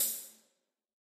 tamborine.ogg